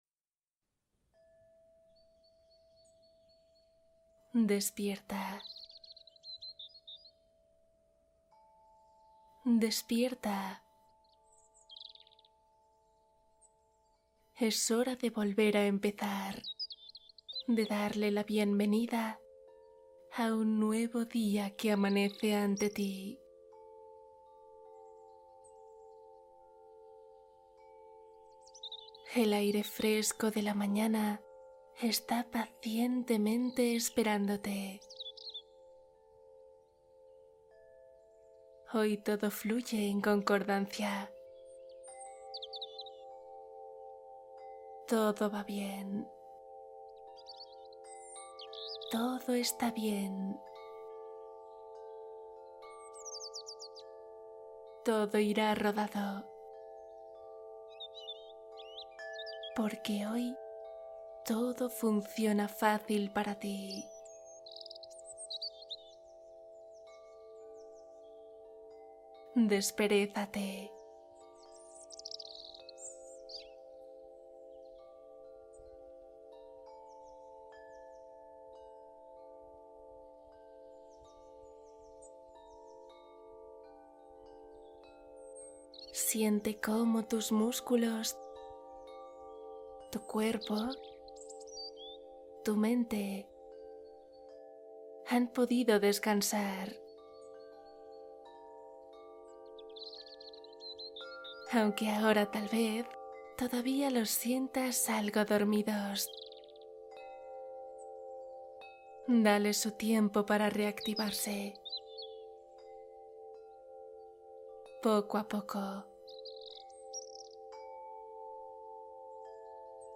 Al despertar, una meditación para iniciar con energía renovada